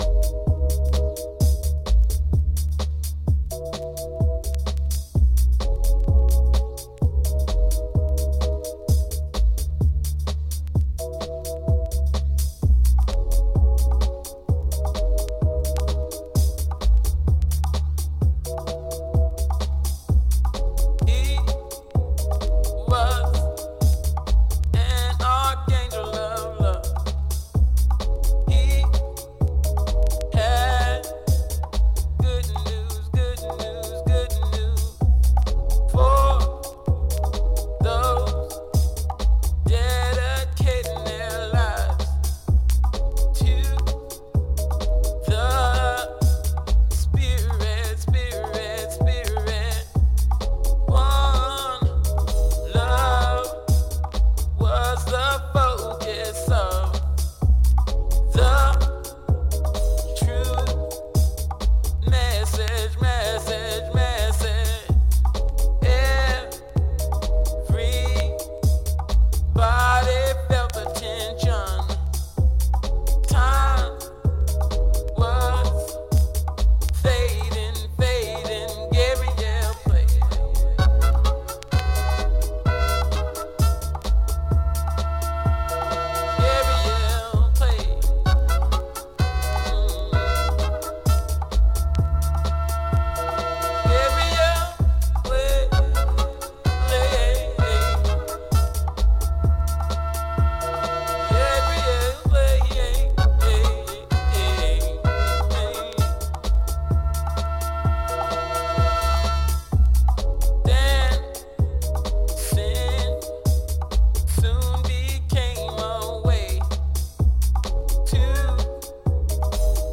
ディープで洗練されたソウル/ジャズ感を含んだ傑作ハウス作品です。